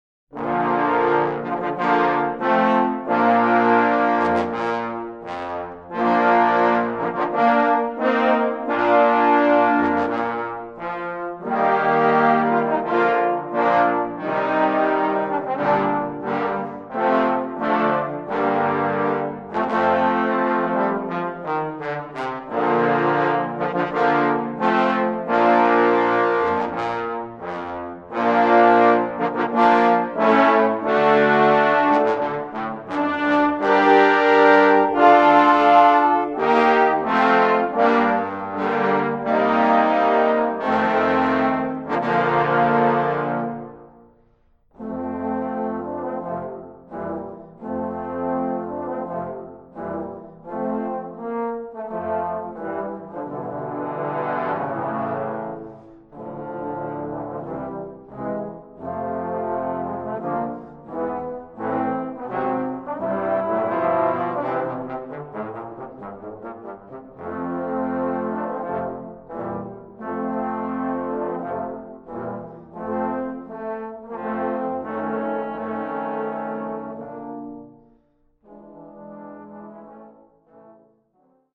Voicing: Trombone Ensemble